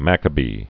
(măkə-bē)